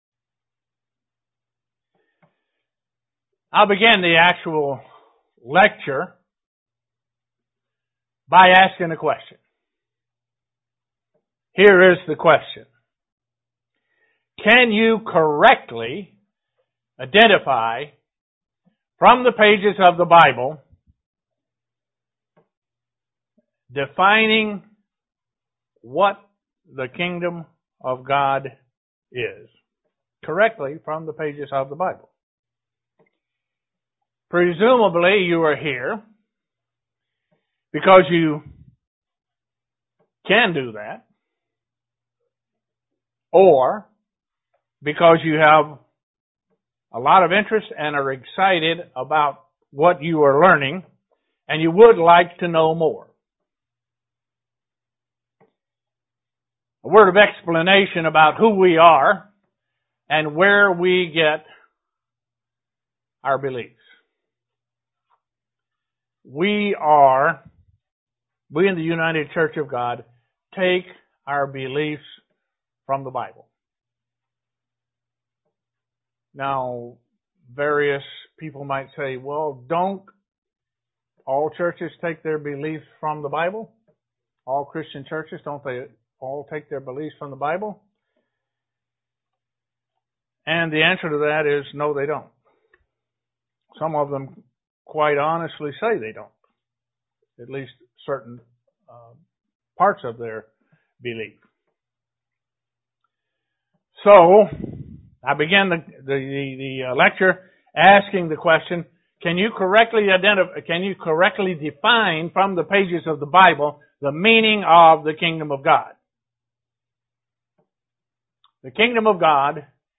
The introductory lecture of the KOG Seminars.
Given in Elmira, NY
Presnet the Biblical definition of the Kingdom of God UCG Sermon Studying the bible?